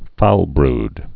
(foulbrd)